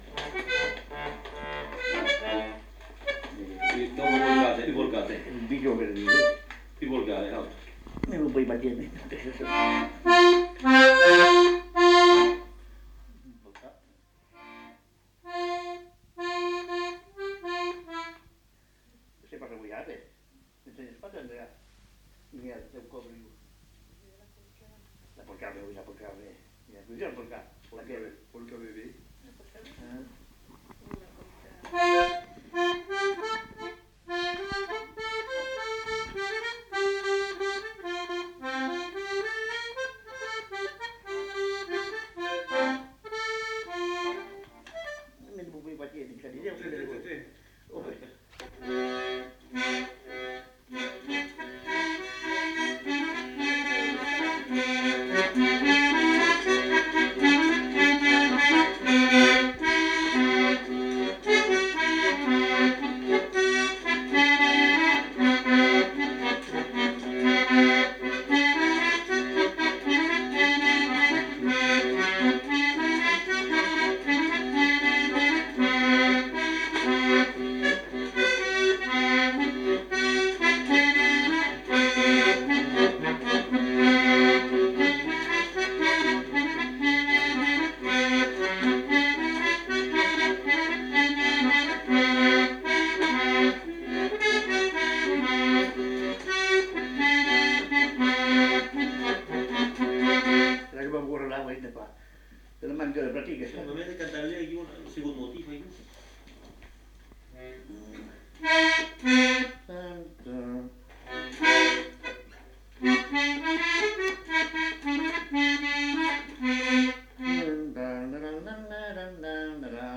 Lieu : Lencouacq
Genre : morceau instrumental
Instrument de musique : accordéon diatonique
Danse : polka des bébés